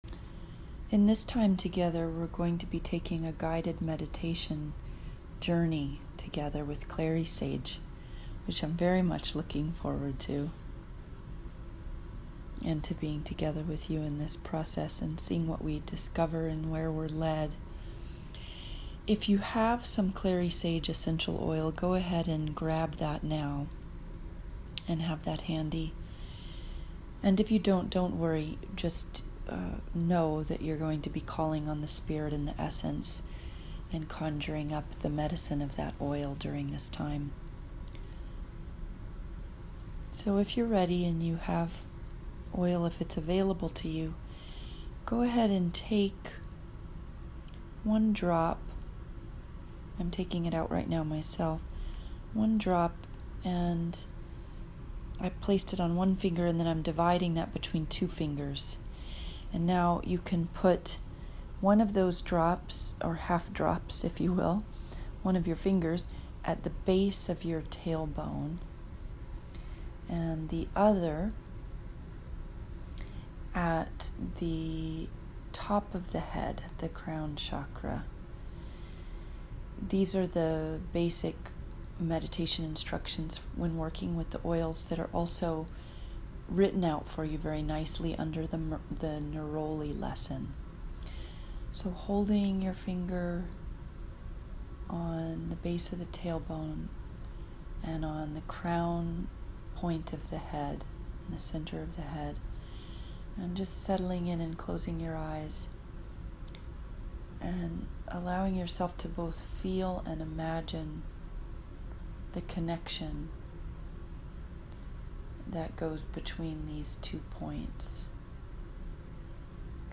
Clary Sage Guided Meditation